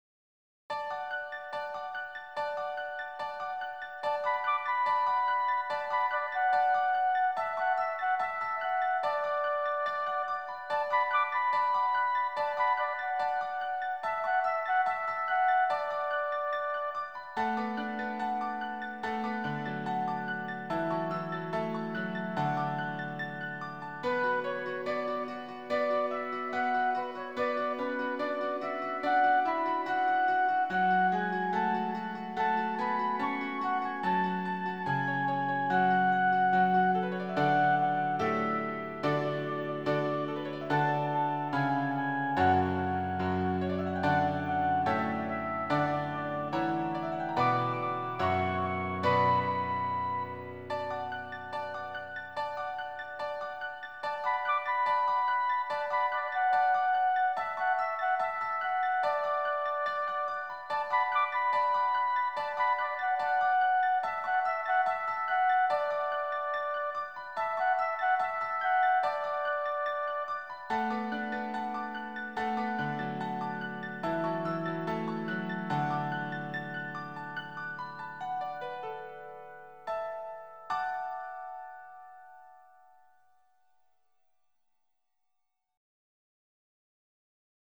挿入歌３